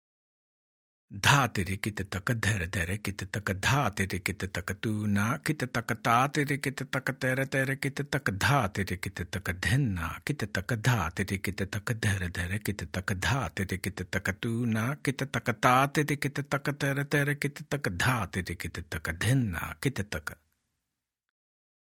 Spoken – Medium